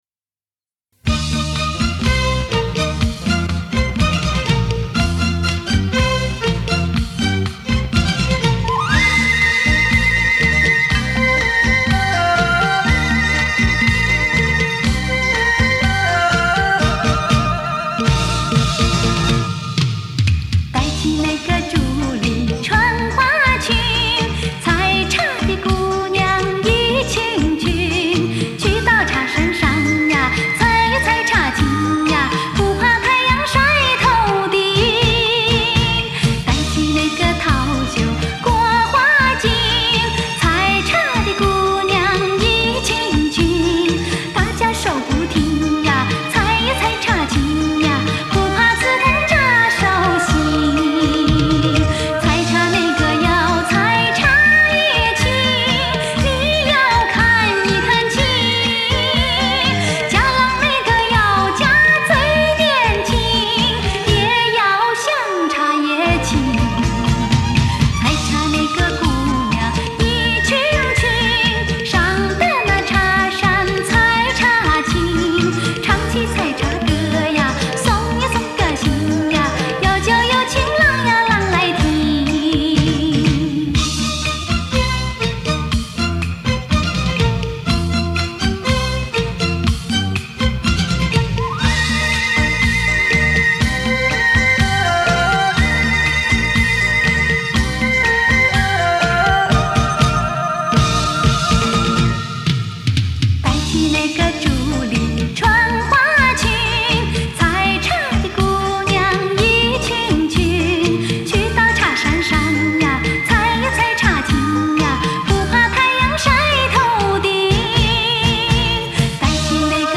福建民歌